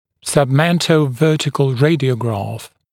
[sʌbˌmentəu’vɜːtɪkl ‘reɪdɪəugrɑːf] [-græf][сабˌмэнтоу’вё:тикл ‘рэйдиоугра:ф] [-грэф]рентгенограмма в подбородочной проекции